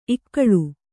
♪ ikkaḷu